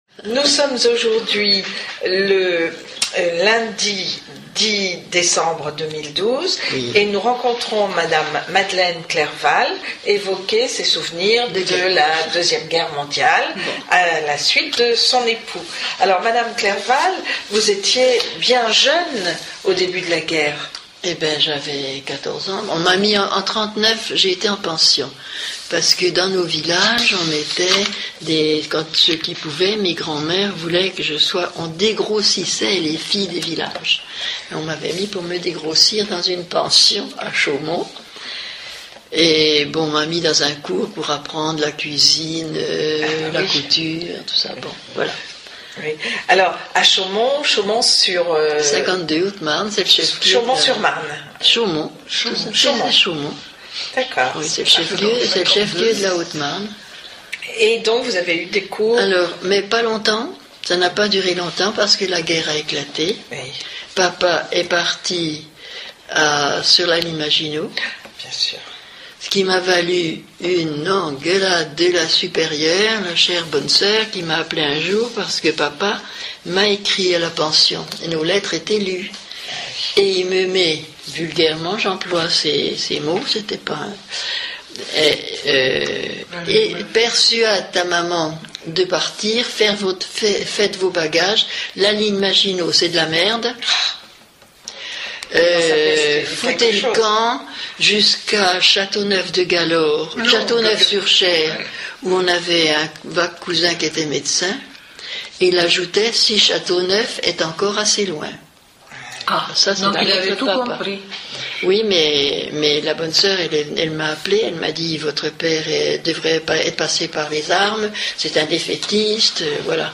Cette interview est issue du fonds d'enregistrements de témoignages oraux relatifs à la Seconde Guerre mondiale , collectés dans le cadre du projet de recherche Mémoires de Guerre de l'Université de Caen Basse-Normandie.